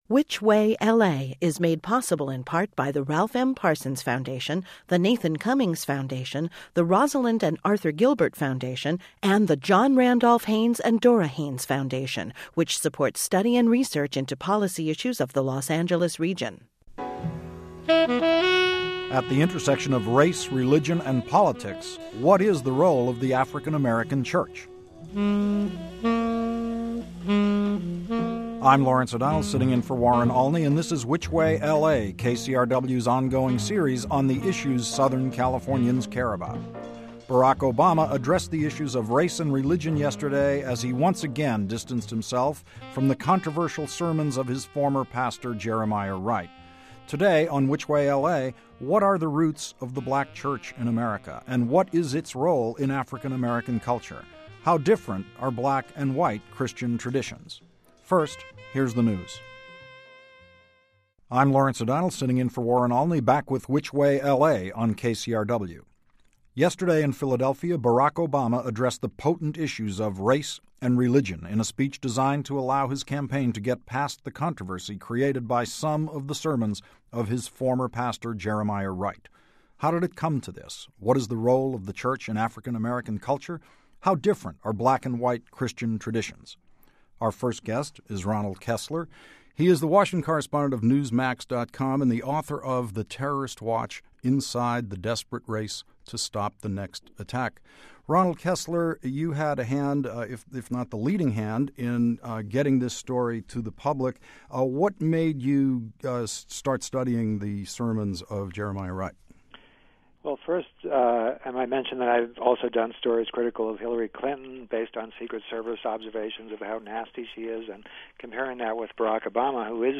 talks to the reporter who sparked the controversy, and discusses the roots of the black church in the US and its current role in African American society.